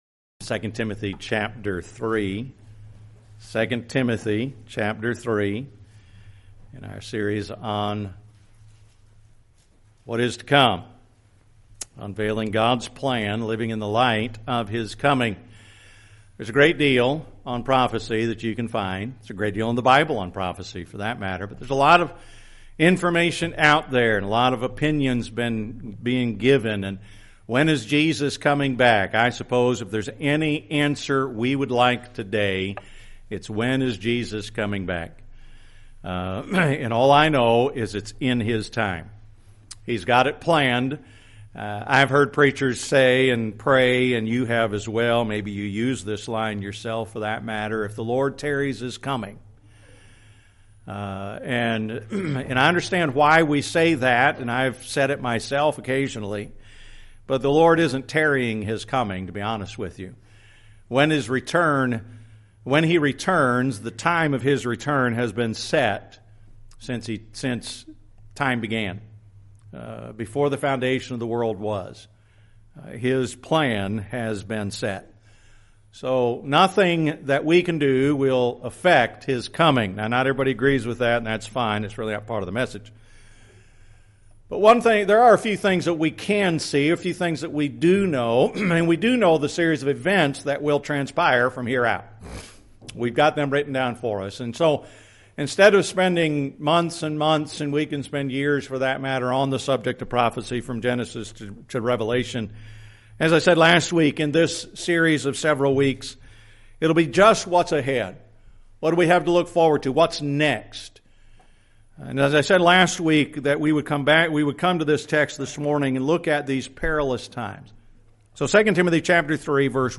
November 2, 2025 – Sunday morning